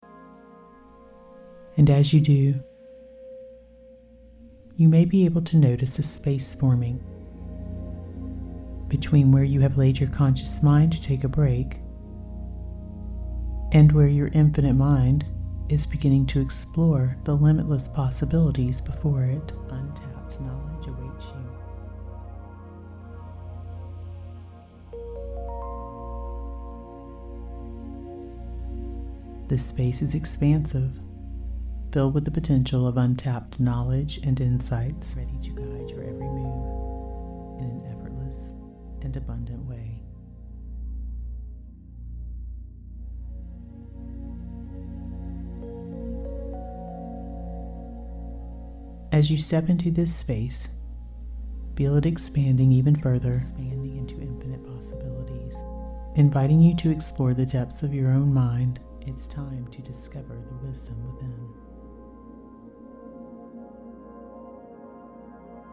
Utilizing alpha waves and deep hypnosis, this manifestation meditation empowers you to move through the spiritual realm and unlock hidden knowledge so you manifest your deepest desires.
Dual-Induction Technology: Experience the cutting-edge dual-induction technology, delivering both synchronized and alternating audio to create a dynamic 3D sound environment.
Alpha Wave Track: The inclusion of an alpha wave track in this session is designed to enhance your clarity, creativity, and focus.
Utilizing good quality stereo headphones is crucial, as the subtle auditory layers are designed to engage your brain more effectively and impress your subconscious on a deeper level.